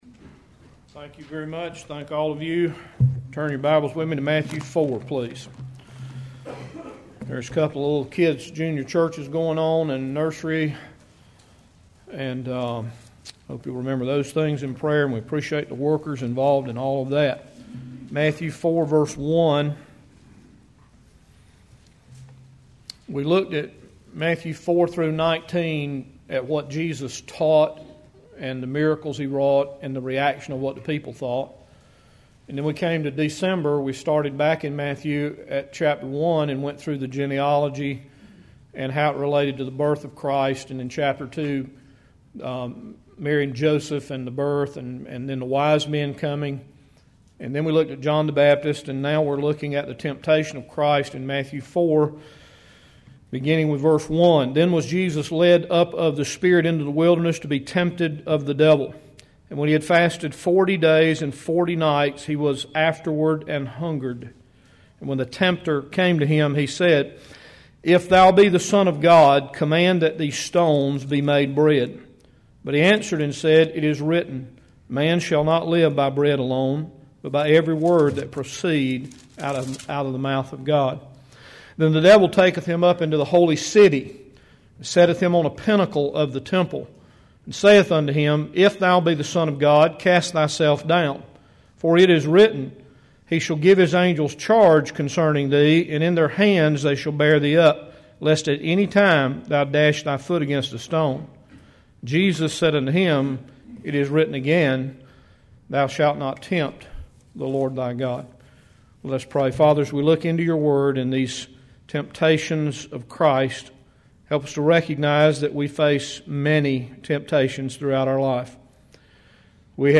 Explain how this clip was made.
January 29, 2012 AM Service Matthew Series #48 – Bible Baptist Church